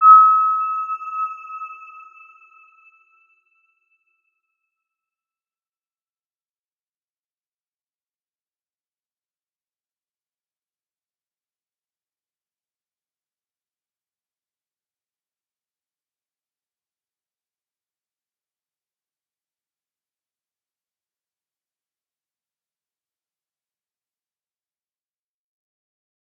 Round-Bell-E6-p.wav